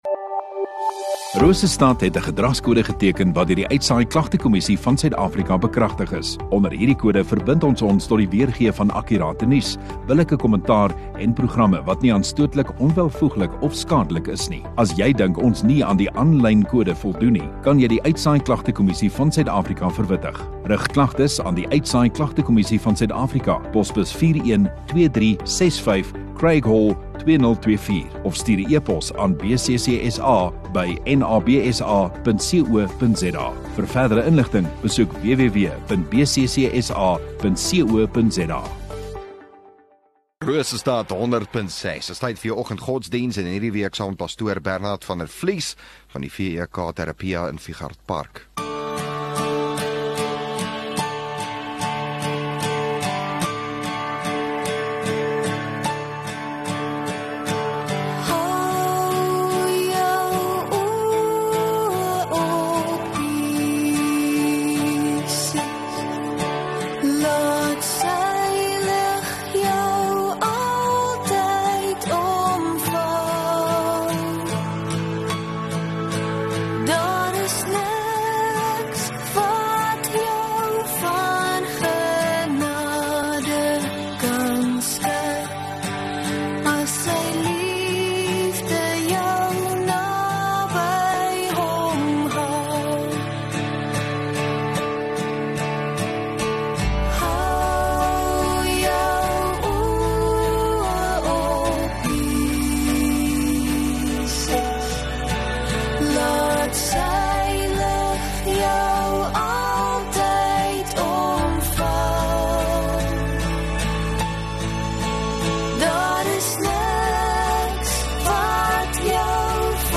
22 Jul Dinsdag Oggenddiens